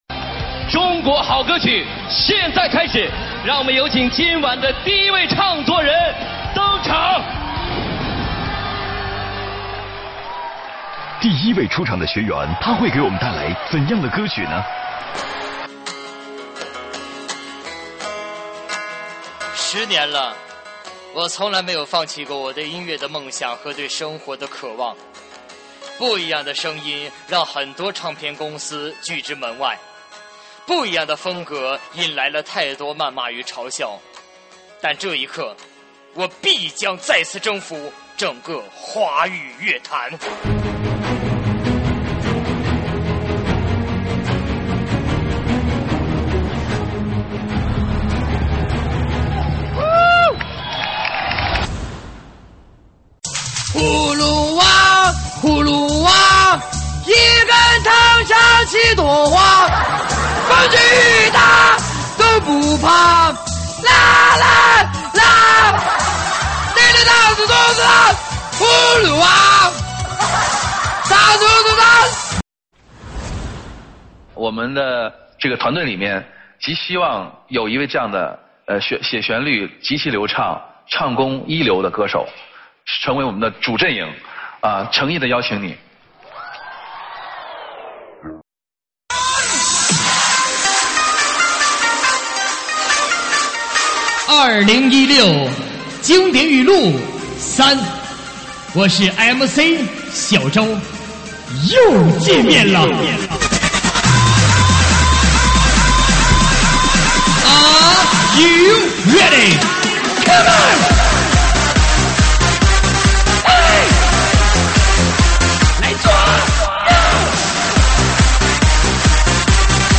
喊麦现场